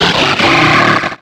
Cri de Colhomard dans Pokémon X et Y.